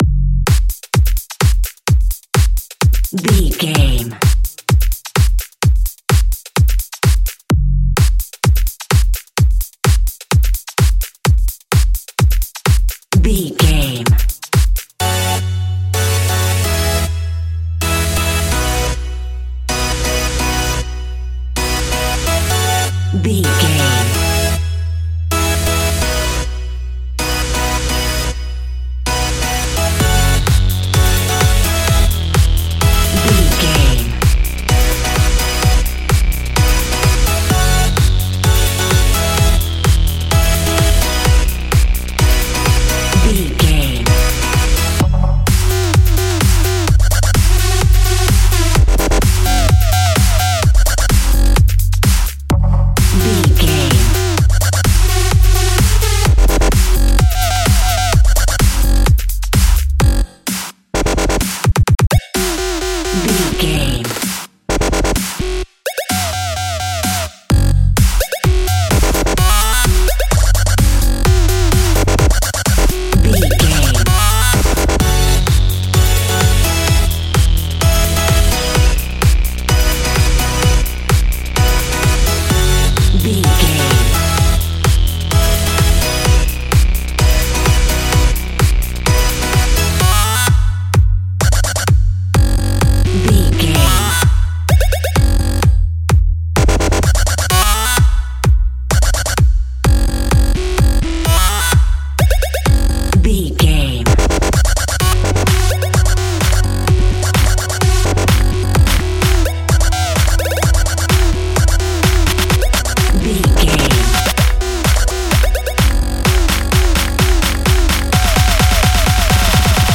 Mixolydian
Fast
aggressive
powerful
driving
uplifting
hypnotic
industrial
drum machine
synthesiser
breakbeat
energetic
synth leads
synth bass